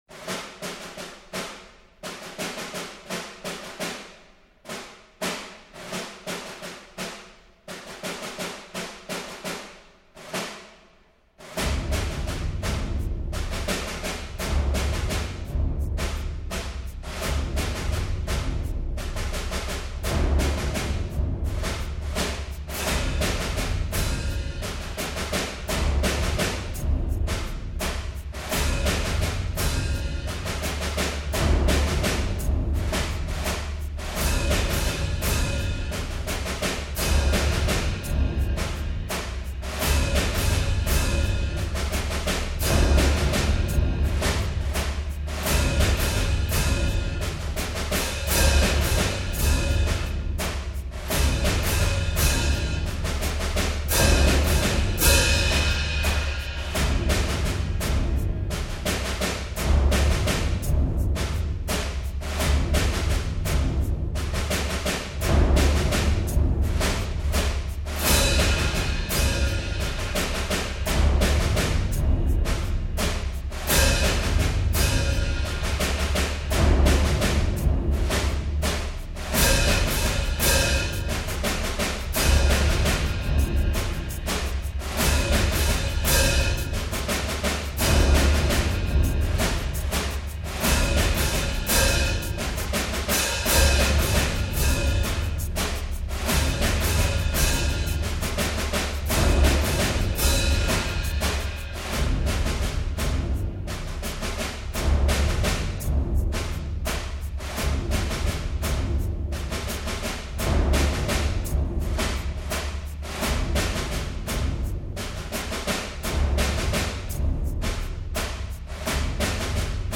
drums without pads